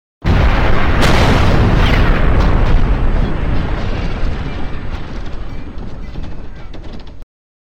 Explosion With Chaos Sound Effect Free Download
Explosion With Chaos